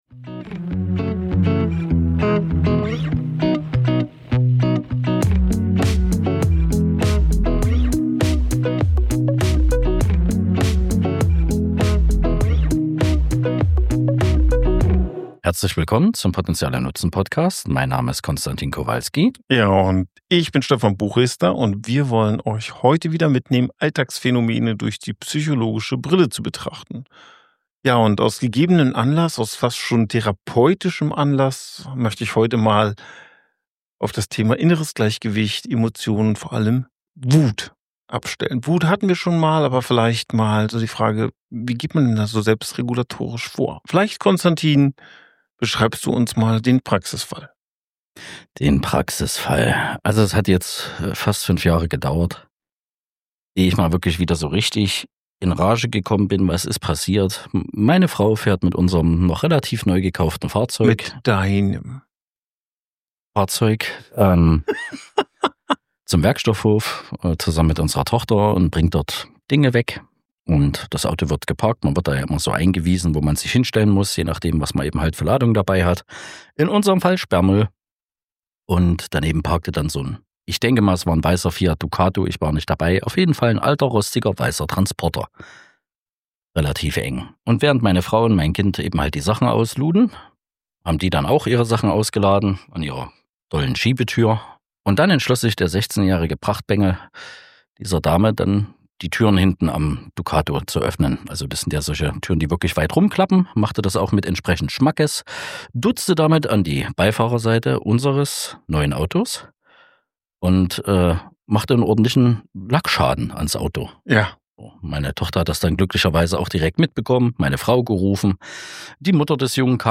Mit gewohntem Tiefgang und einer Prise Humor analysieren die beiden den Vorfall unter psychologischen Gesichtspunkten. Sie sprechen über Primäremotionen, Selbstwirksamkeit, mentale Kosten und wie das System der „Anständigen“ funktionieren kann.